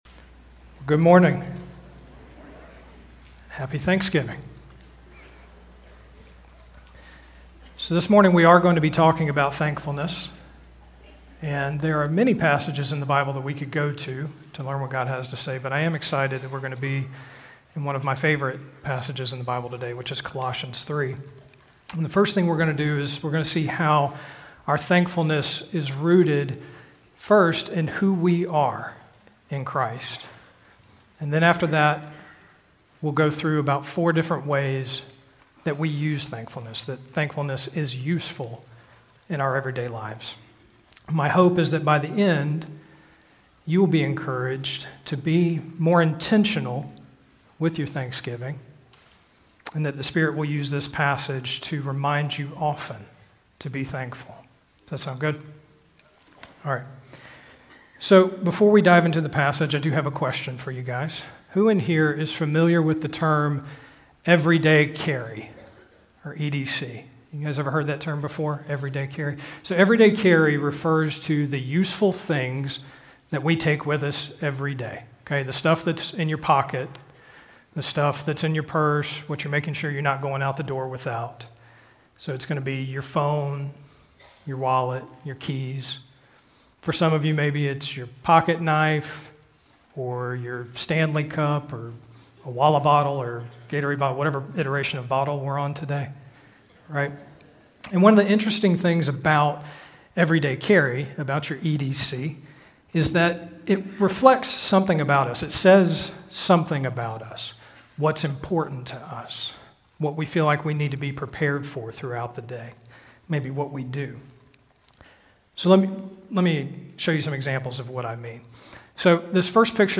Prev Previous Sermon Next Sermon Next Title Risen in Christ, Thankful in Life – 11/30/25 Teacher Admin Date November 30, 2025 Scripture Colossians , Colossians 3:12-17 Prev Previous Sermon Next Sermon Next Notes Slides Audio